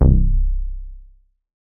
MoogCar 006.WAV